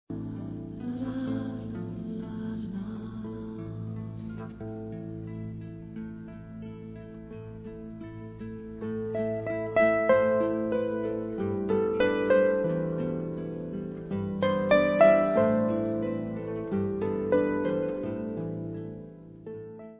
Celtic-worldbeat-folk-pop//special box